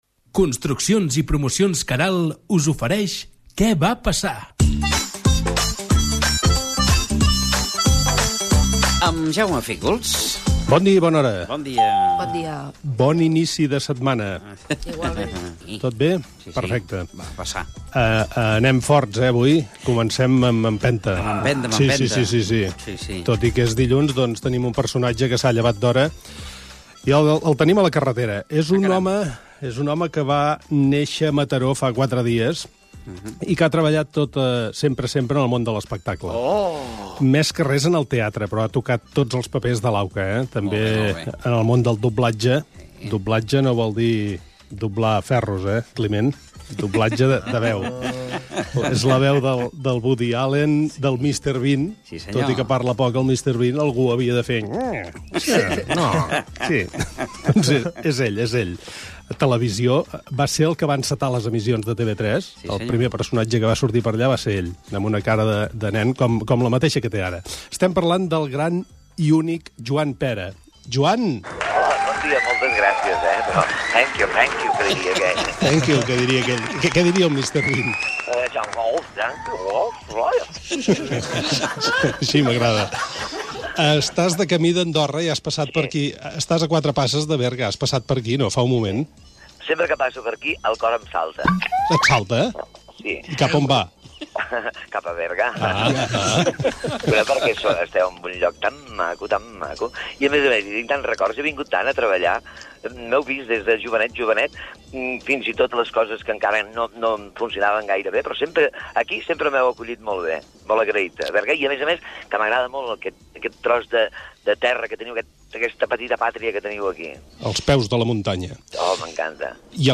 Espai "Què va passar?". Careta del programa amb publicitat i entrevista telefònica a l'actor Joan Pera
Entreteniment